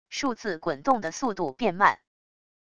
数字滚动的速度变慢wav音频